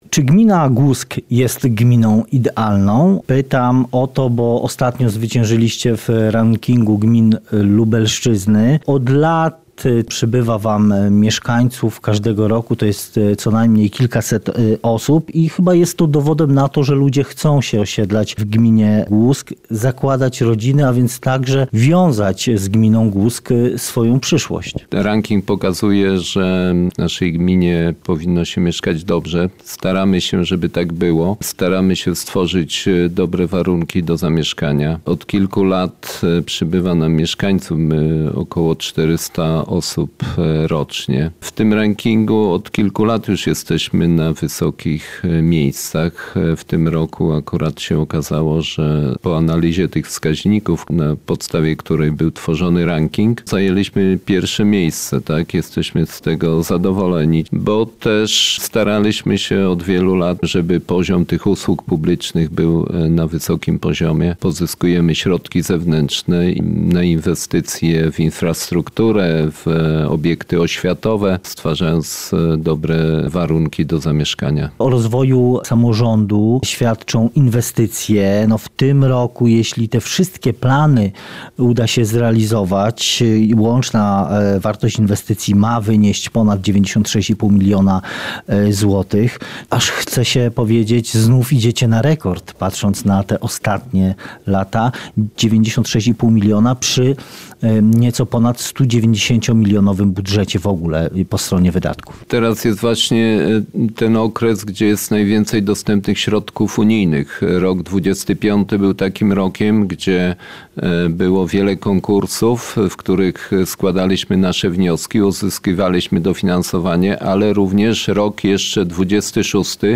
Wójt najlepszej gminy w regionie: Staramy się stworzyć dobre warunki do zamieszkania